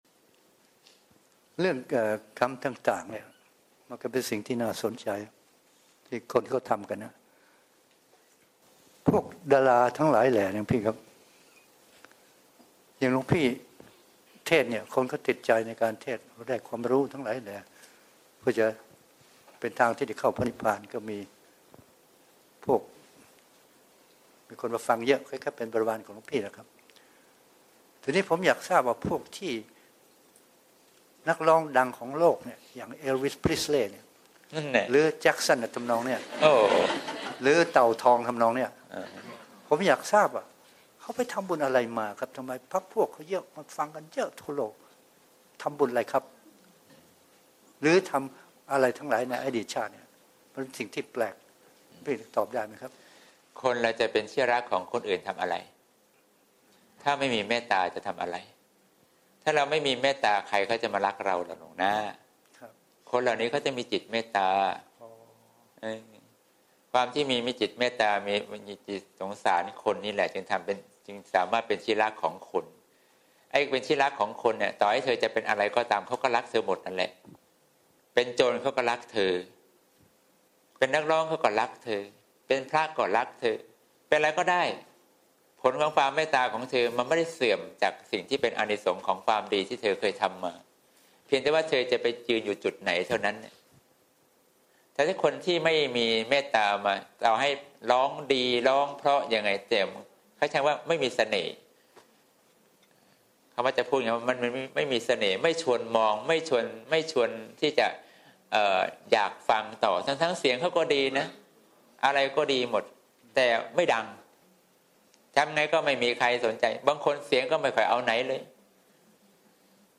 เสียงธรรม